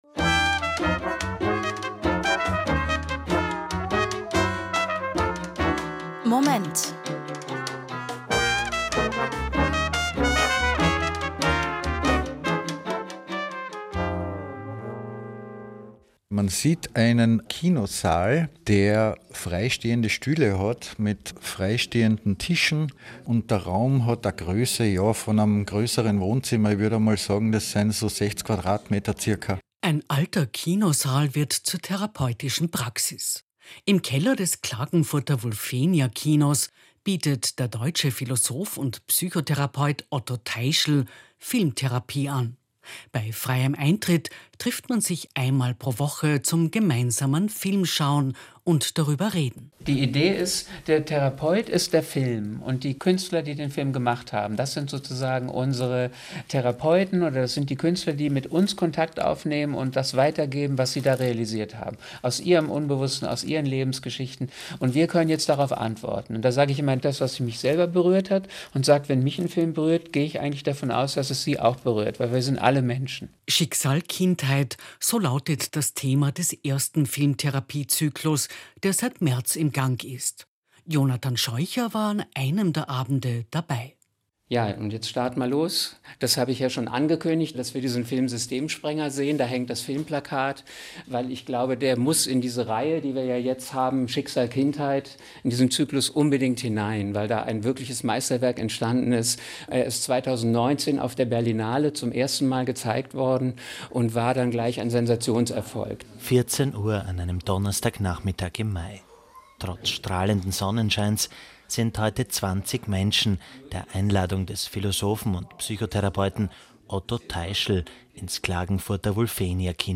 Ö1 war live dabei